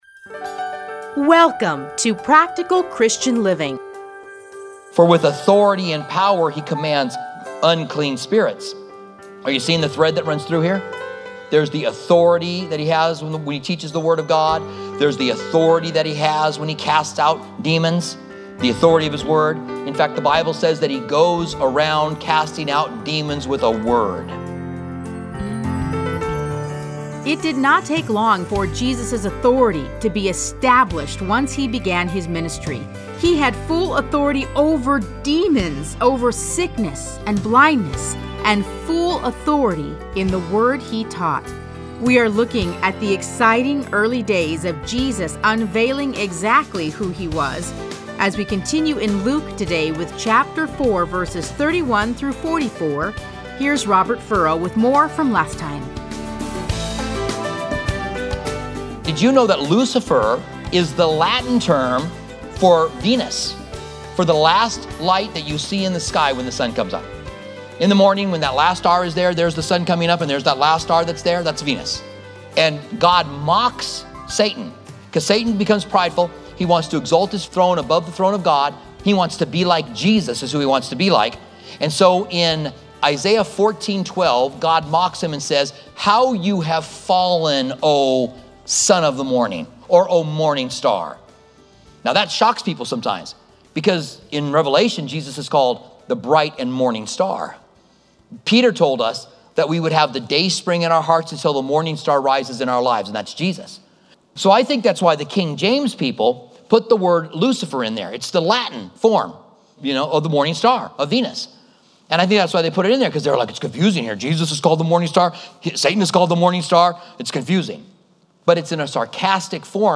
Listen to a teaching from Luke 4:31-44.